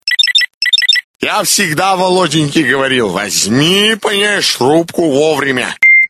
Главная » Рингтоны » Рингтоны пародии